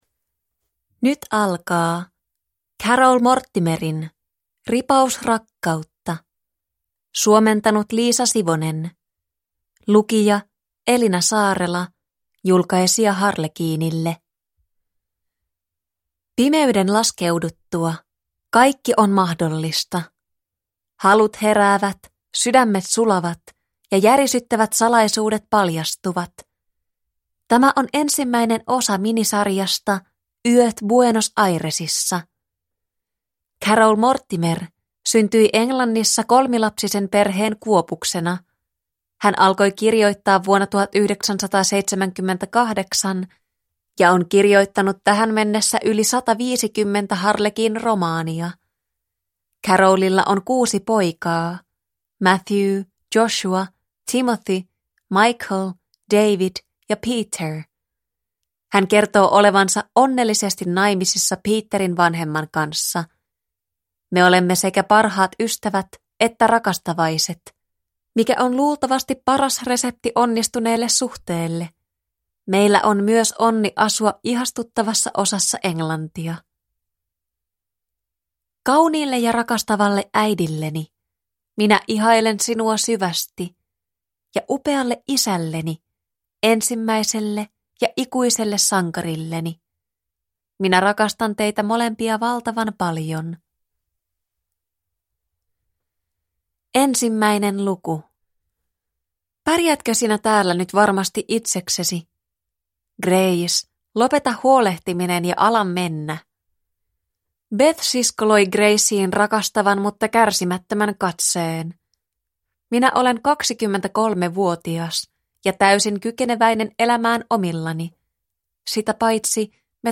Ripaus rakkautta – Ljudbok – Laddas ner
Språk: Finska